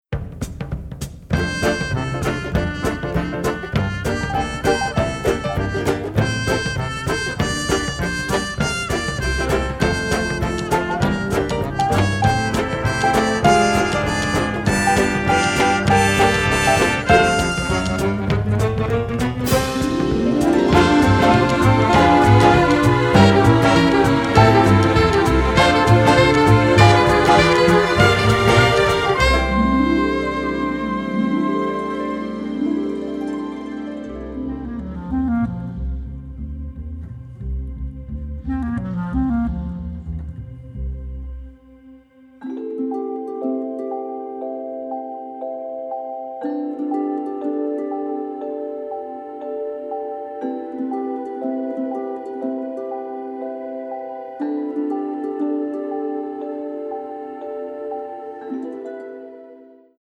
The music was recorded in magnificent sound in London